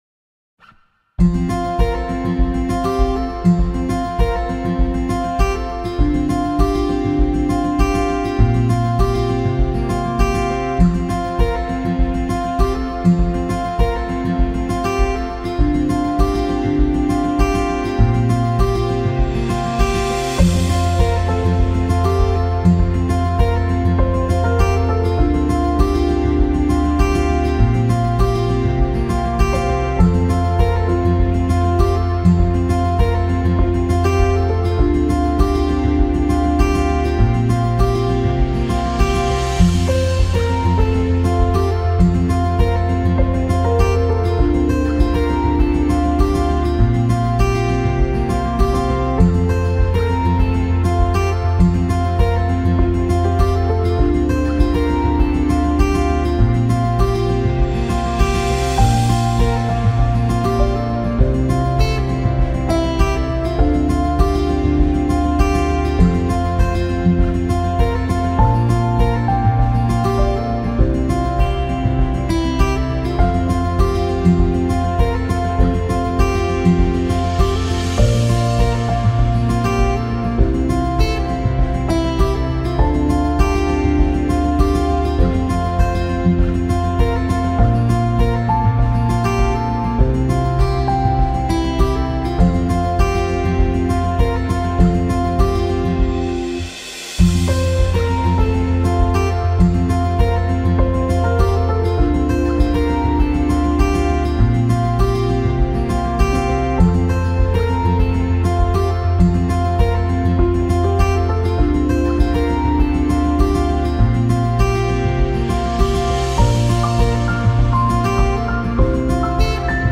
Acoustic.mp3